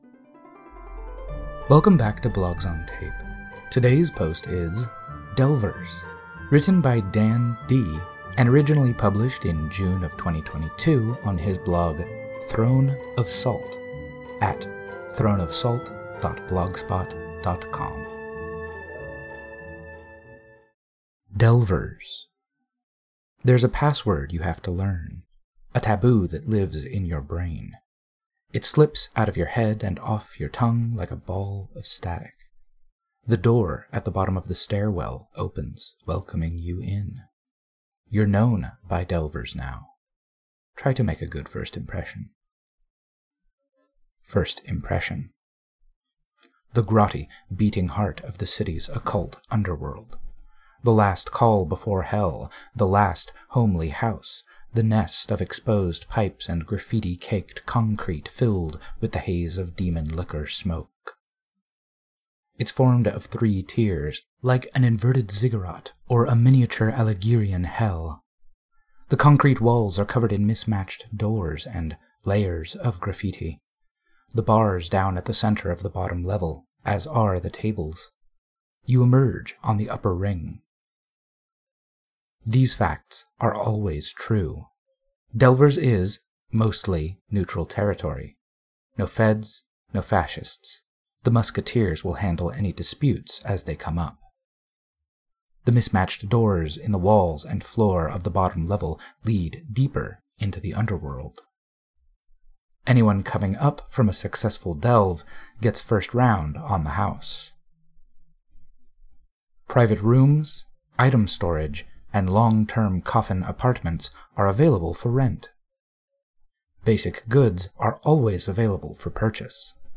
OSR Blog Posts. Read out loud.